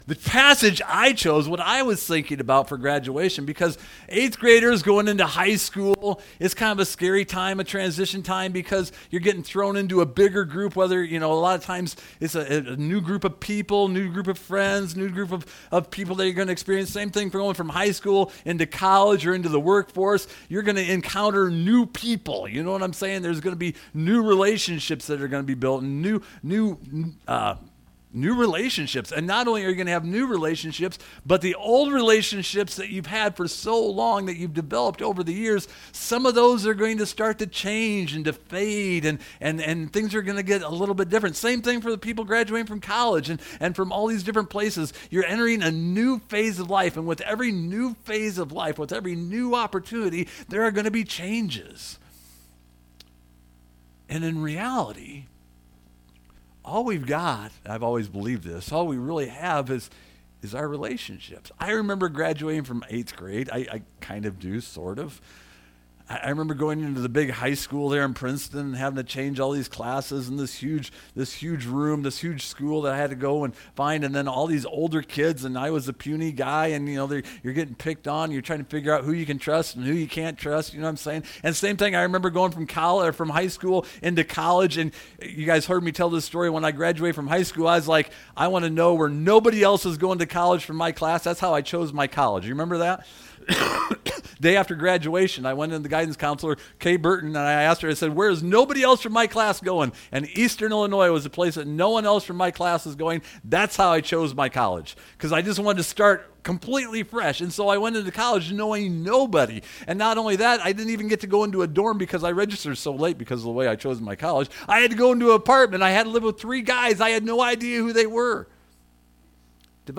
Graduation challenge and the second sermon in the series Faith and Finances. Challenge was given to keep your life from the love of money and a reminder that God provides security, joy, comfort, help, confidence, and contentment. Graduates were challenged to store up rewards in heaven and not on earth.